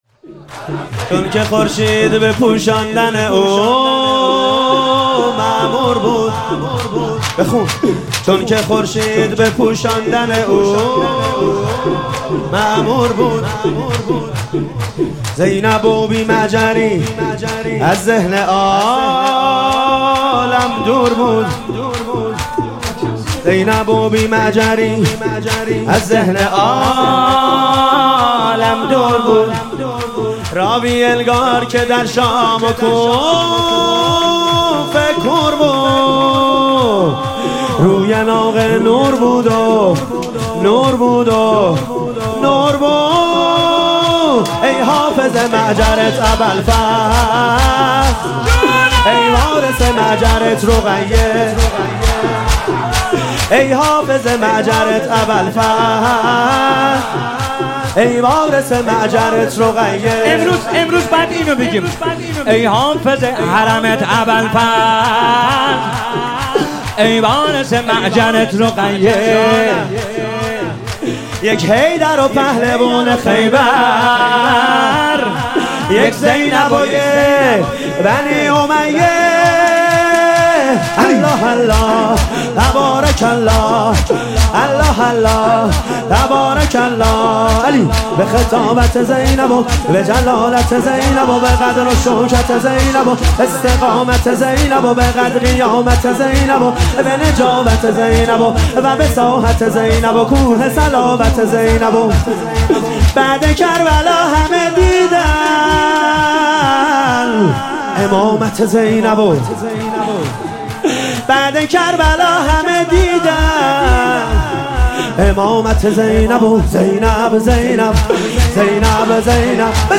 هیئت هفتگی 20 آذر